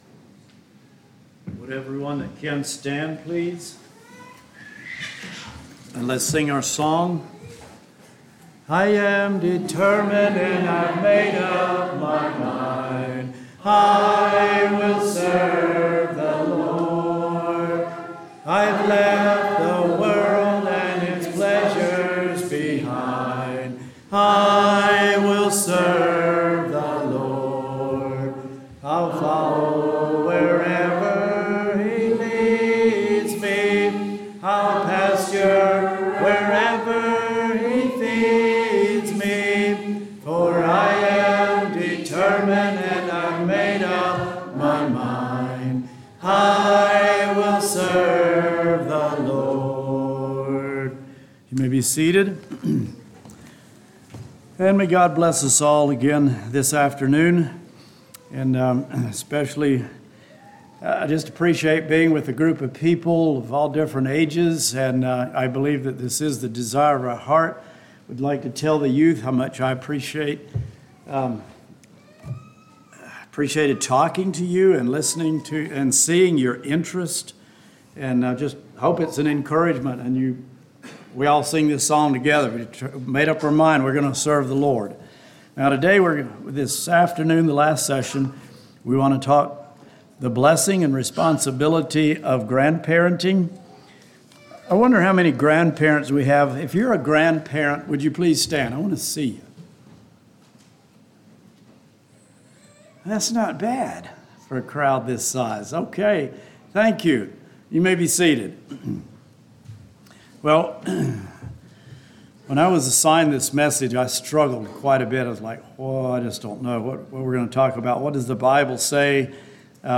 Sermons
Smithdale | Bible Conference 2025